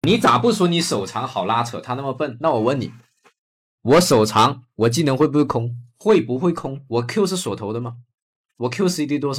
动态社交媒体教程语音
通过清晰、高能量的AI语音立即吸引注意力,专为社交媒体教程、技术演示和病毒式叙事优化。
文本转语音
高能量
我们的AI提供有力、类人的语调,能够突破社交信息流的噪音。